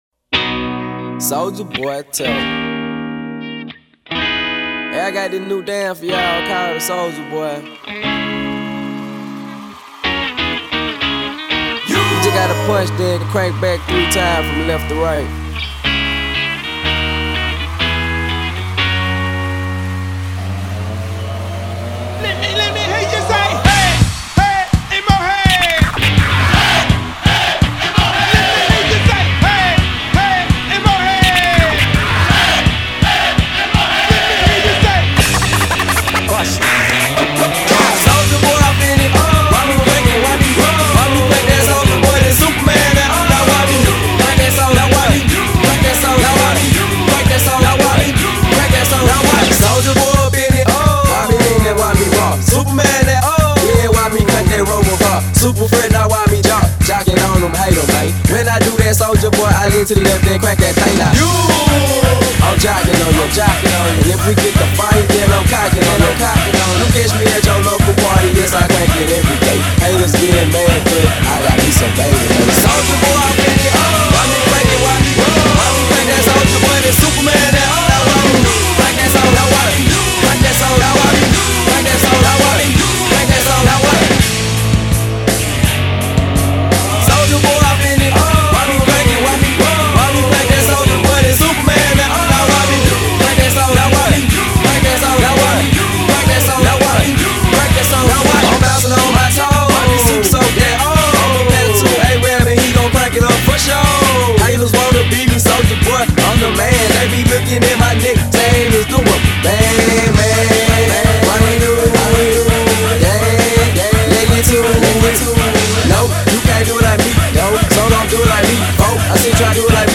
Bootleg
Acapella
Instrumentale